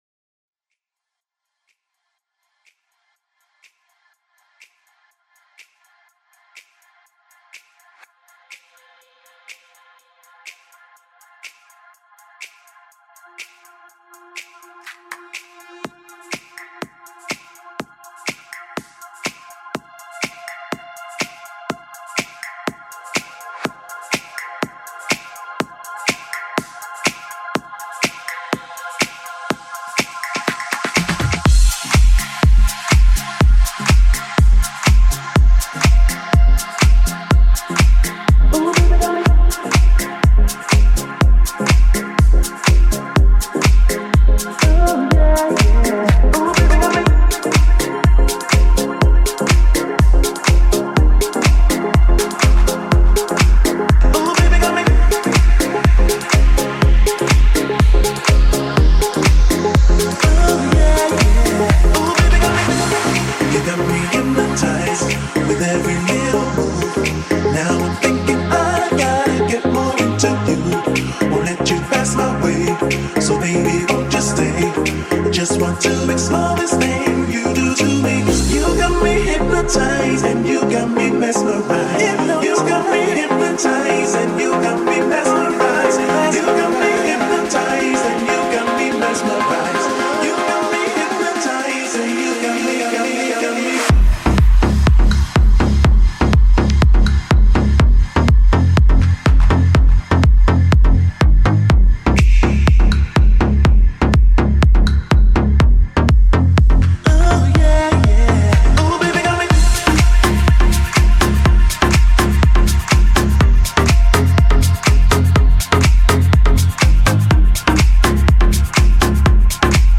Очень легкий и позитивный музняк )
Красиво и атмосферно!